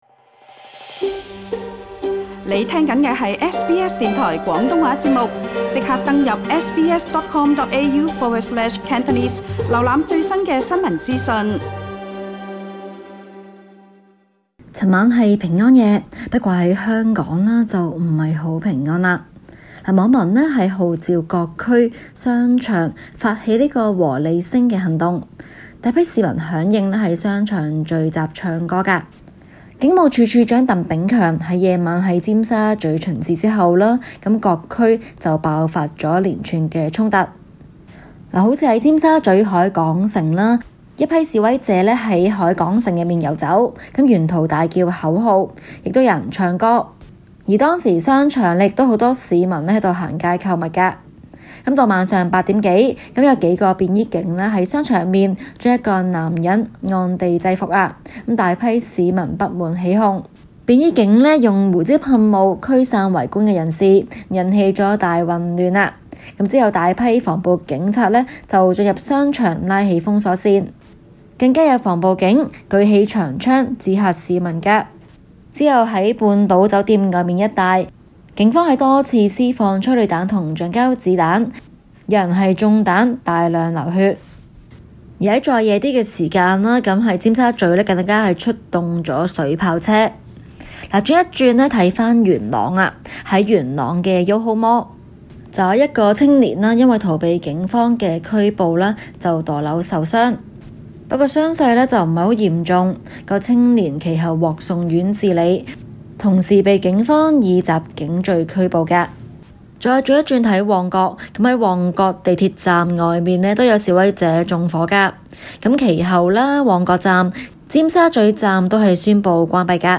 Source: AP SBS广东话播客 View Podcast Series Follow and Subscribe Apple Podcasts YouTube Spotify Download (11.43MB) Download the SBS Audio app Available on iOS and Android 昨日是平安夜，但香港并不平安，多区发生了警民冲突。 其中受影响地区包括尖沙嘴、旺角、沙田及元朗，而警方更出动催泪烟及水炮车驱散群衆。